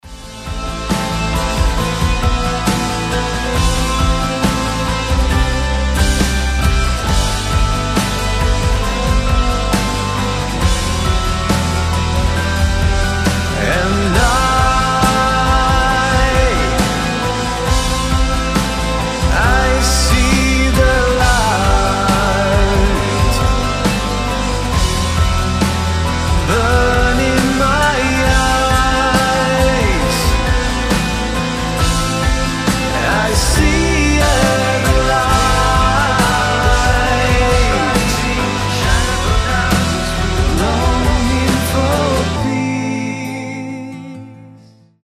грустные
Alternative Rock
пост-рок